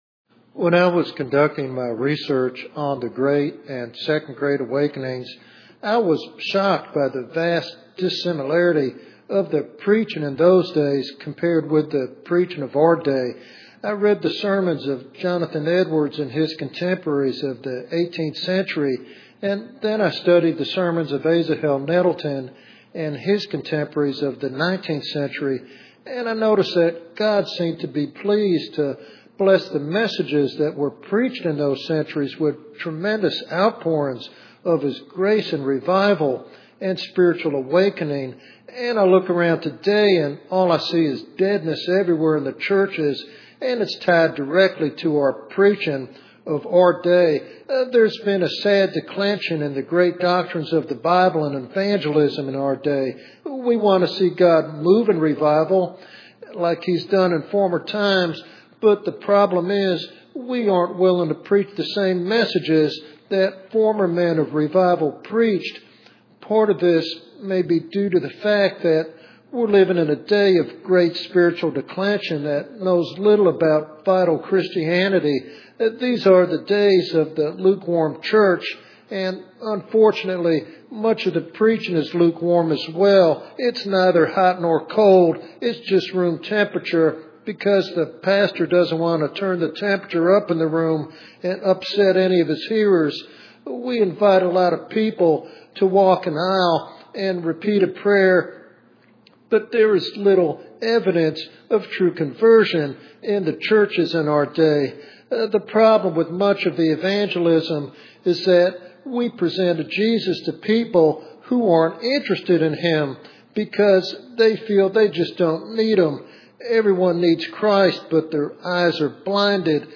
This sermon challenges believers and preachers alike to confront the shallow messages prevalent today and to embrace a robust, transformative gospel that leads to true conversion and revival.